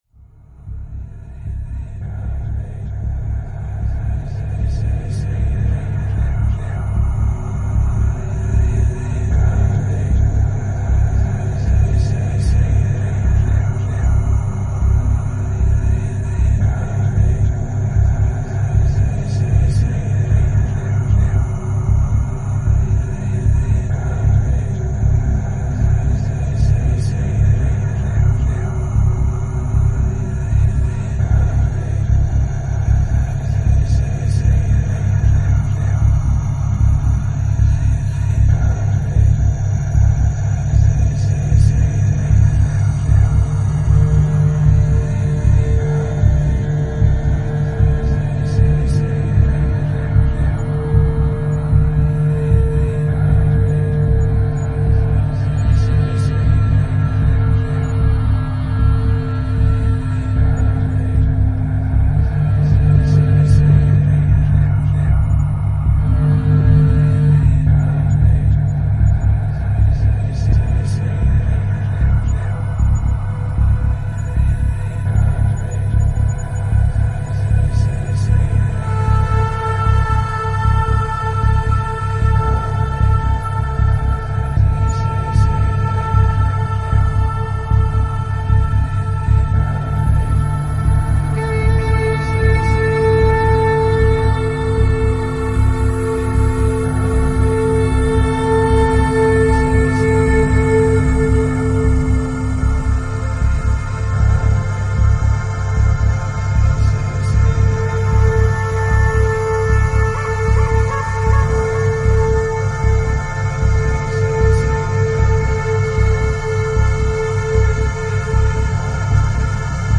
Flute
Synth modular